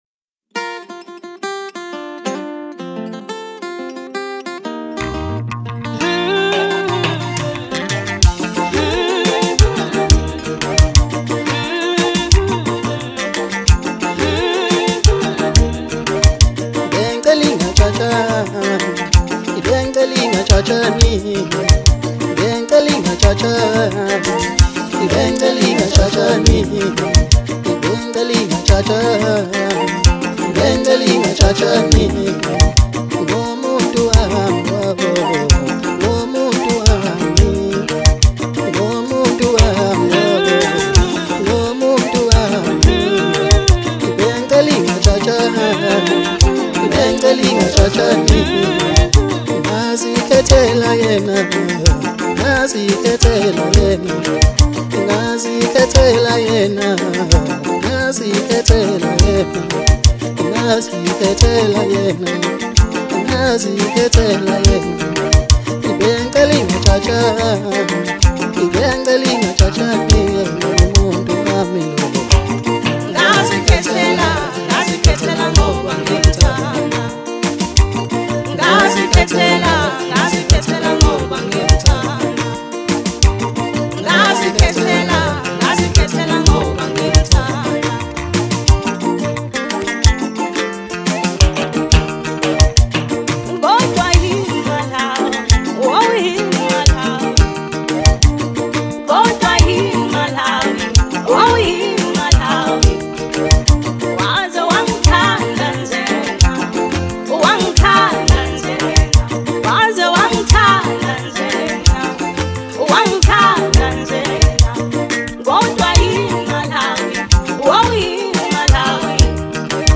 Home » Maskandi » DJ Mix » Hip Hop